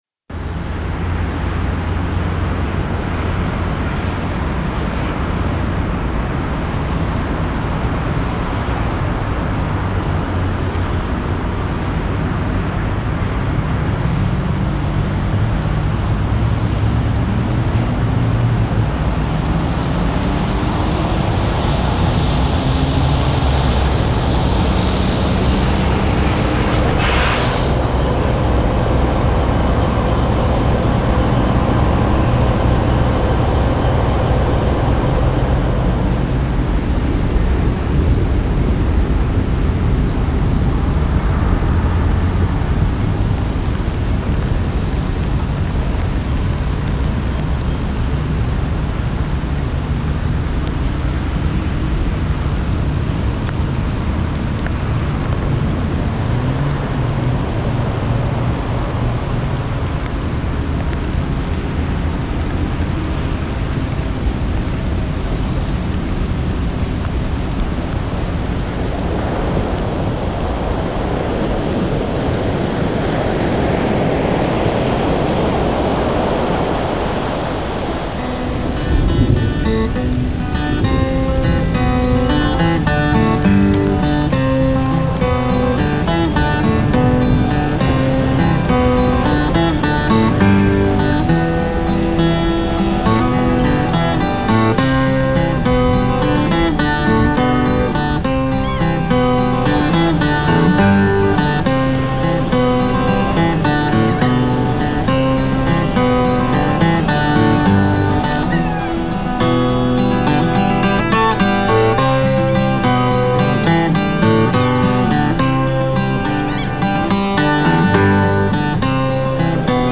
Under a full moon, a National Park Service ranger gave a tour of the haunted places on Ocracoke Island, North Carolina.
Stereo.
Using only sound effects and my 12-string guitar. 03:00 The tragic story of Agustus McGuire. 08:45 A tour of Howard Street. And the murder of Jim Dandy. 14:00 Insanity overtakes Mag Howard. 18:00 Fanny Pearl McWilliams Rayhan, and her premonition about her own death. 25:40 The story of Blackbeard and The Pirate’s Cup.